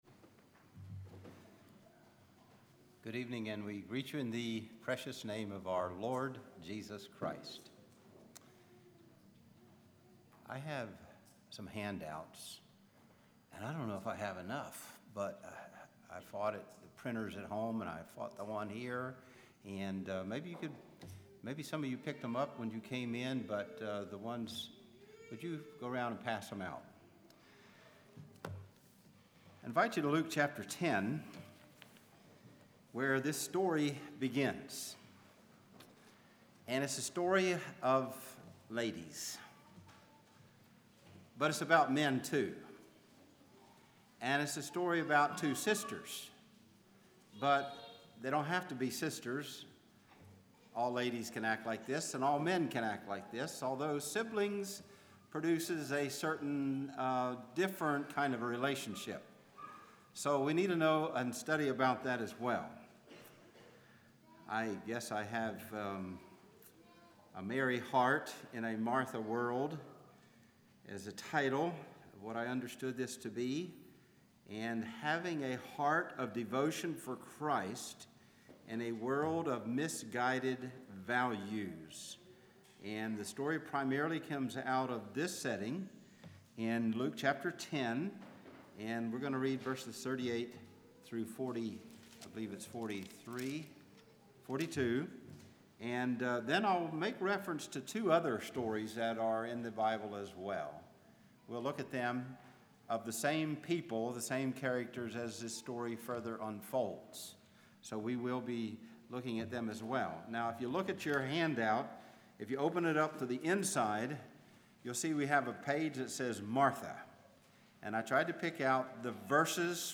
Congregation: Calvary